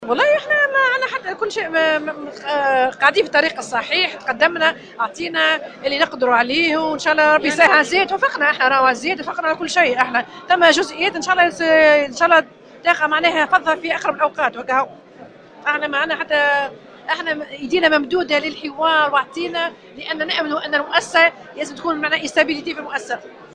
Wided Bouchemaoui, présidente de l’Union tunisienne de l’Industrie, du commerce et de l’artisanat (UTICA), a déclaré aujourd’hui sur les ondes de Jawhara FM que les négociations sociales concernant les augmentations salariales du secteur privé ont avancé.